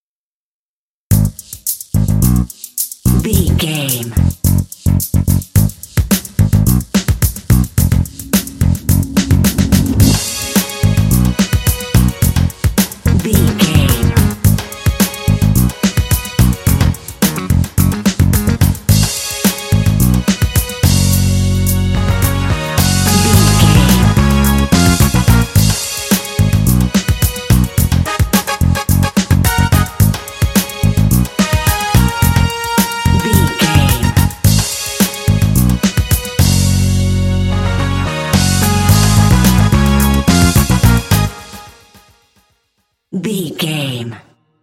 Aeolian/Minor
G#
happy
bouncy
groovy
bass guitar
synthesiser
strings
drums
brass
r& b